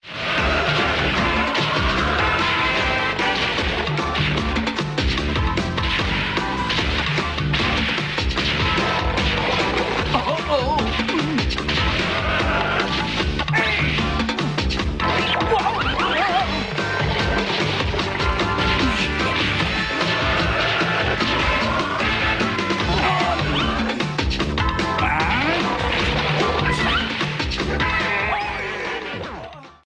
Music sample